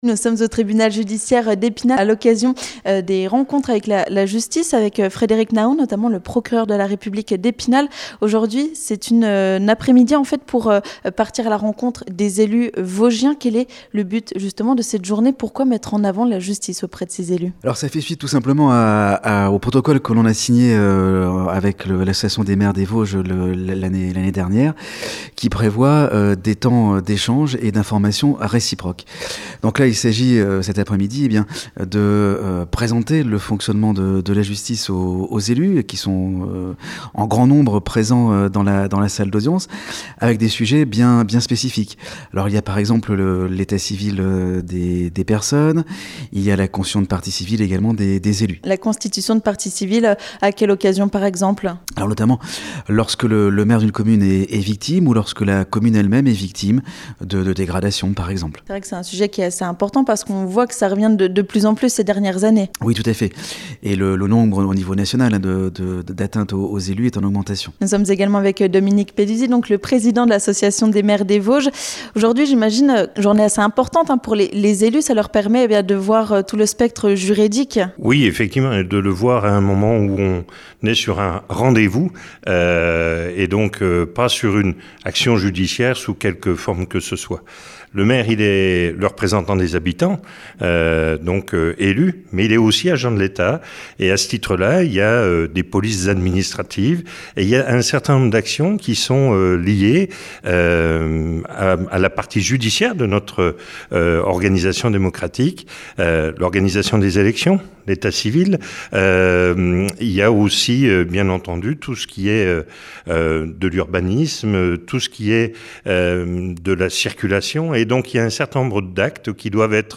On fait le point avec le président de l'AMV 88, Dominique Peduzzi et avec le Procureur de la République d'Epinal, Frédéric Nahon.